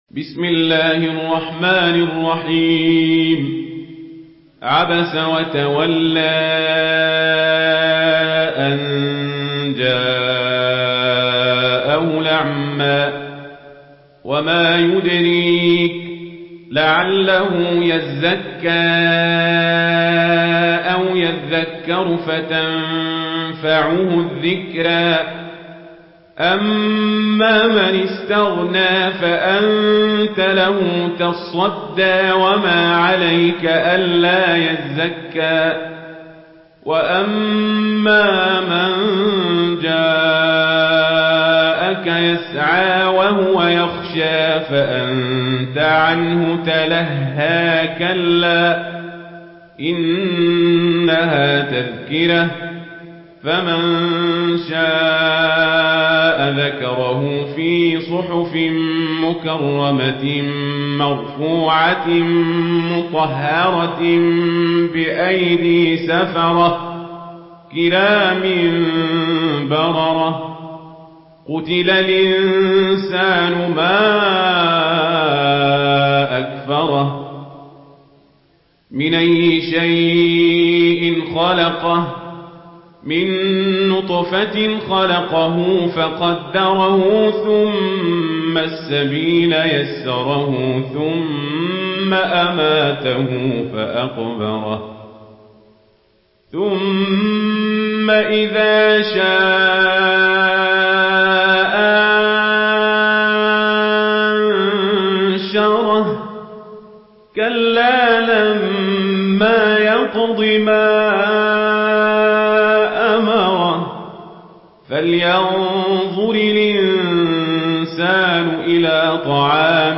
Surah Abasa MP3 by Omar Al Kazabri in Warsh An Nafi narration.
Murattal